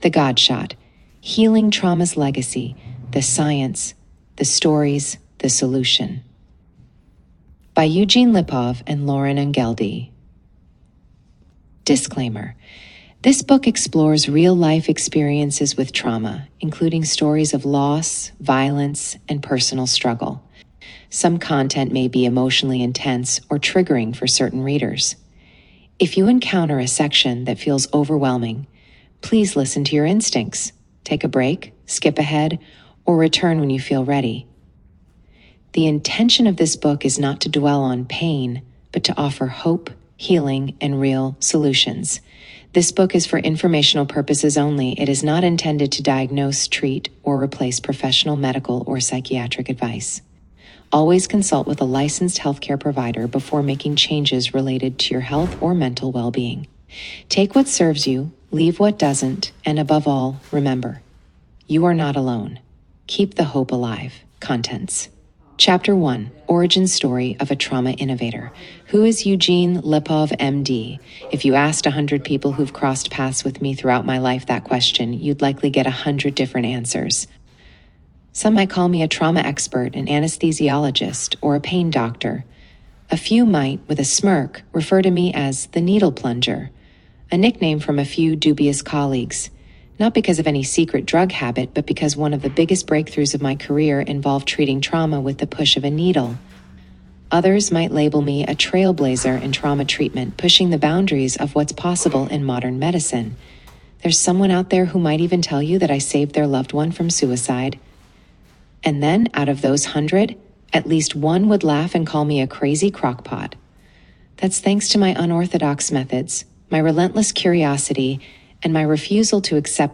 Vista previa de audio
**NOTA: La voz que escuchas pertenece a un increíble actor de voz.
The-God-Shot-audiobook-intro_speed-adj.mp3